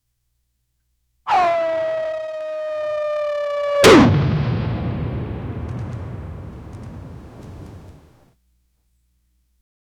three-rapid-military-flar-67a57meb.wav